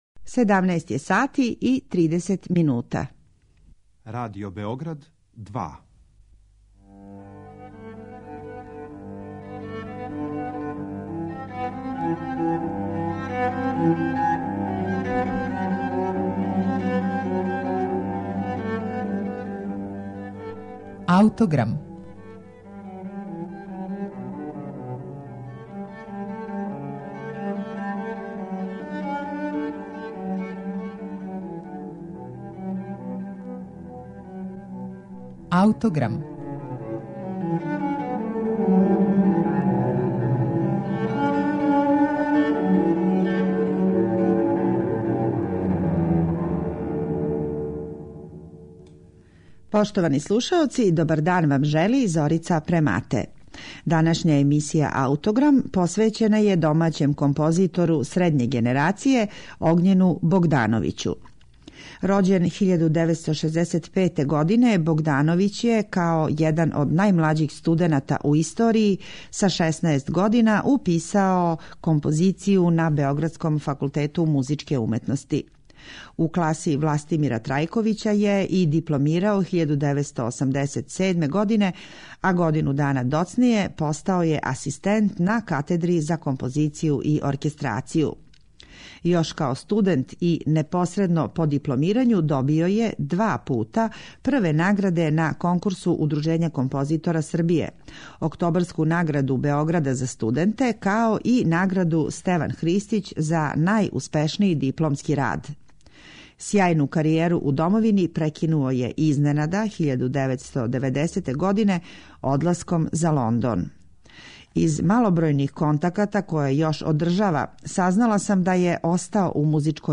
У емисији ћете слушати његово остварење „Ла луна", а извођаћи су Хор и СО РТБ којим диригује Младен Јагушт.